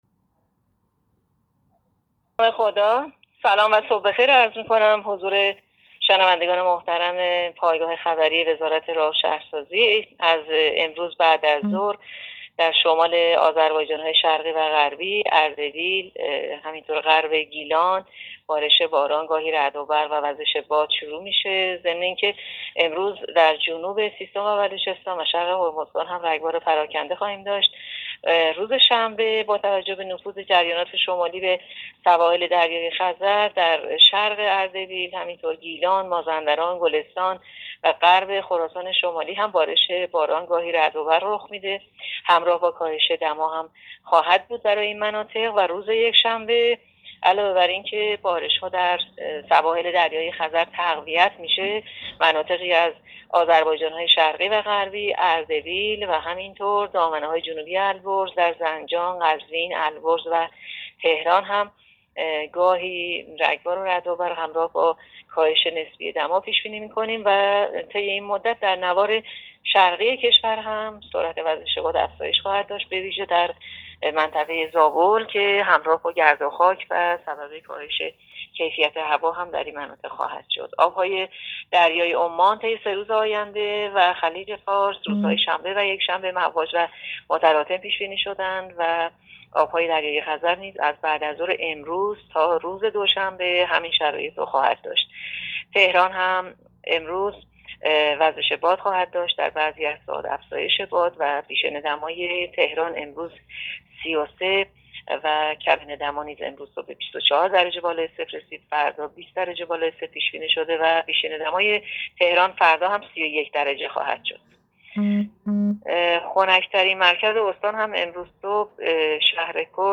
گزارش رادیو اینترنتی پایگاه‌ خبری از آخرین وضعیت آب‌وهوای ۲۷ شهریور؛